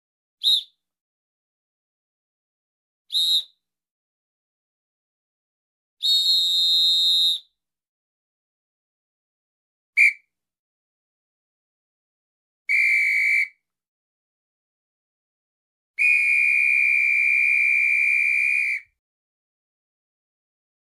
Звуки свиста, свистков
Свисток сотрудника ДПС